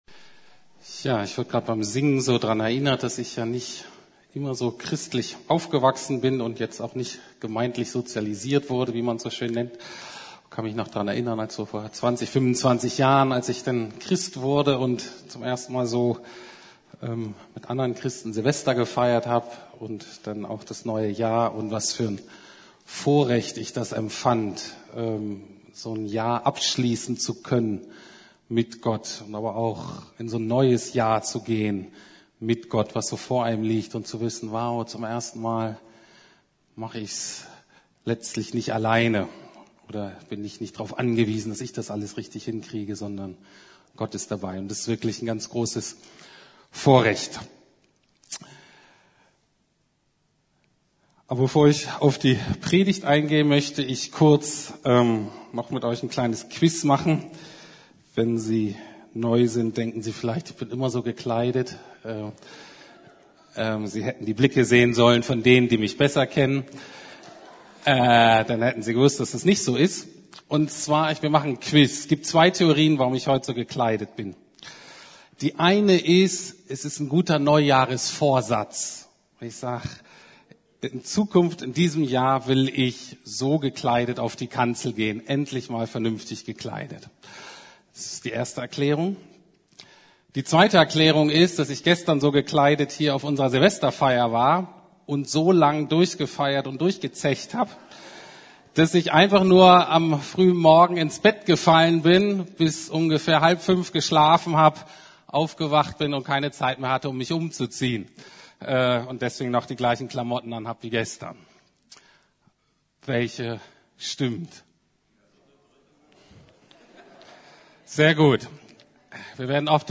Jahreslosung 2015 ~ Predigten der LUKAS GEMEINDE Podcast